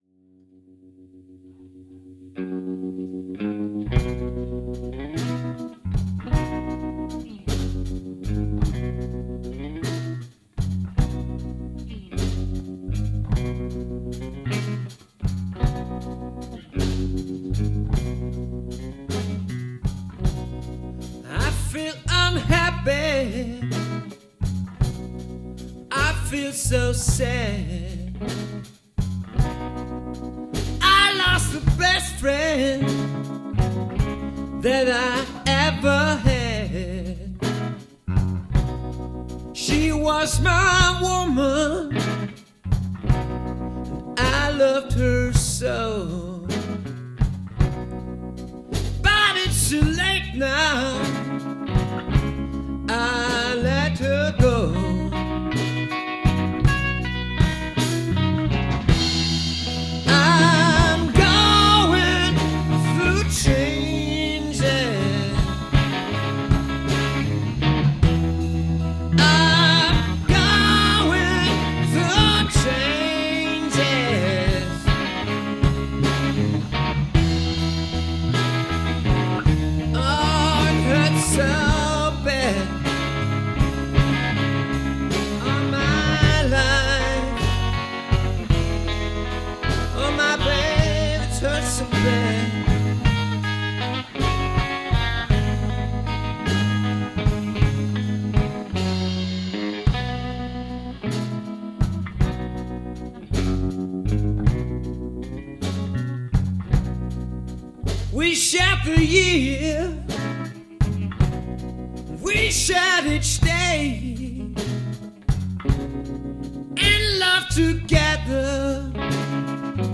rock and hard rock
vocals, guitar
bass, backing vocals
drums, percussion
Mitschnitte aus dem Proberaum